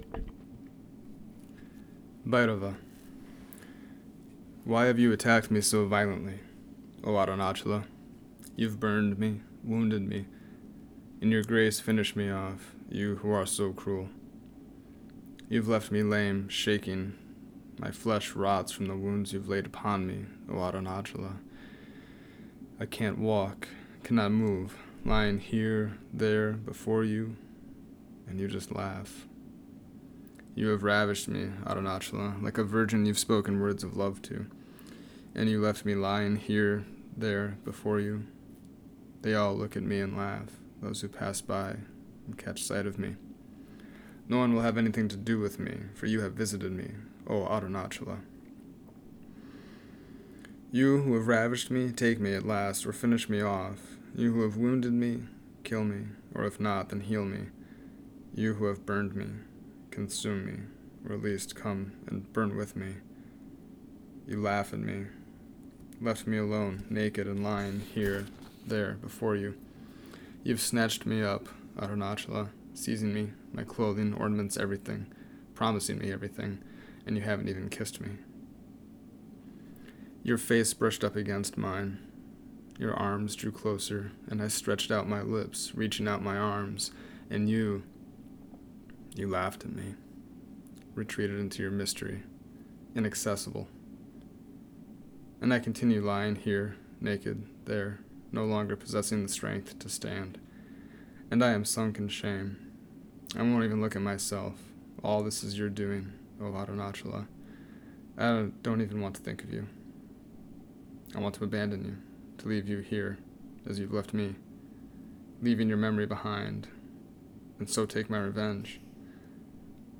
Here is another reading of one of my translations of Swami Abhishiktananda’s poems.